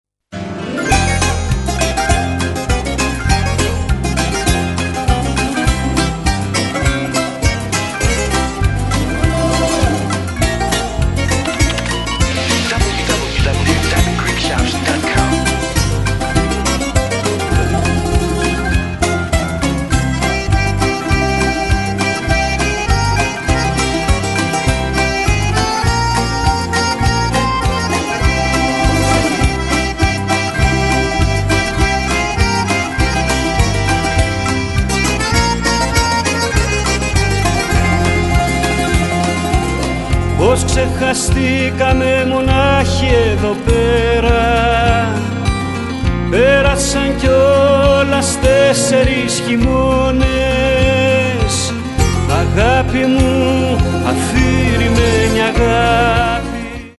all of best hits by the top-selling light rock duo